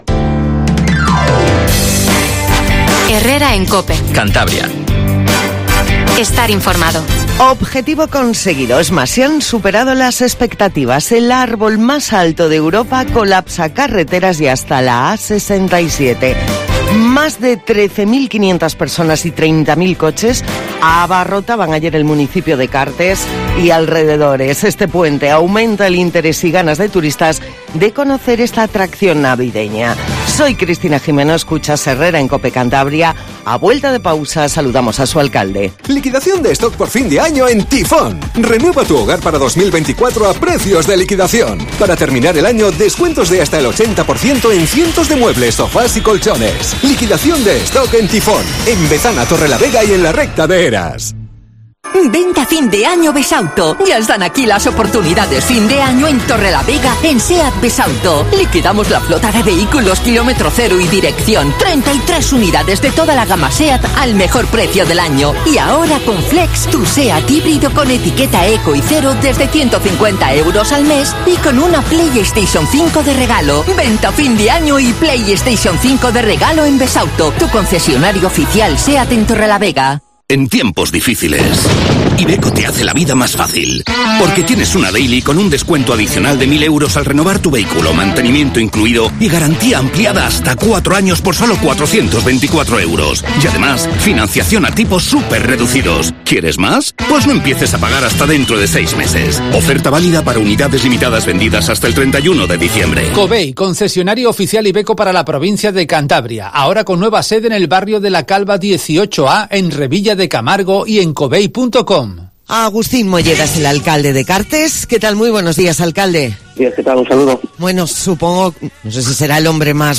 AUDIO: Objetivo cumplido en Cartes, máxima expectación para ver el árbol de Navidad más grande de Europa. Entrevistamos a su alcalde Agustín Molleda.